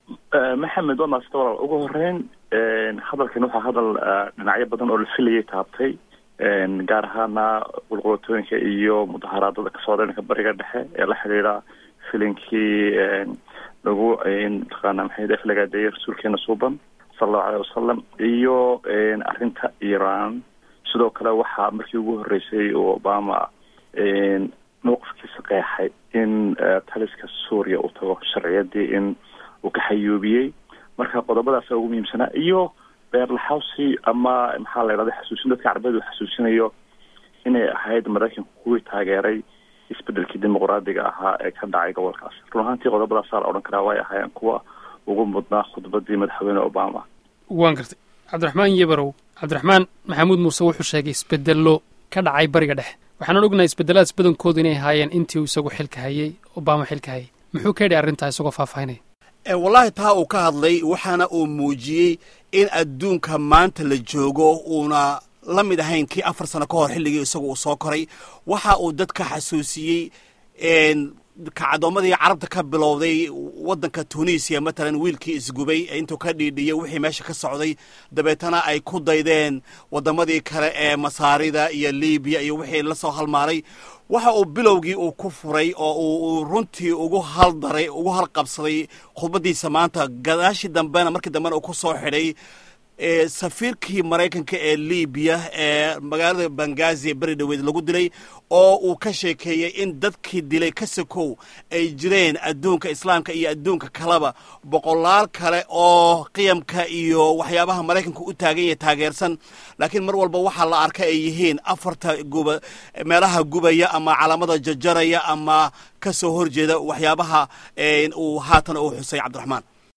Hadalkii Madaxweyne Obama